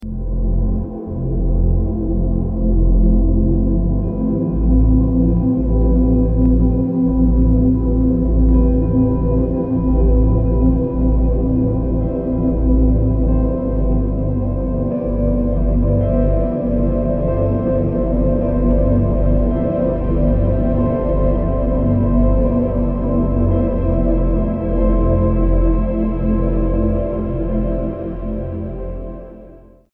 121 432 deep snores later sound effects free download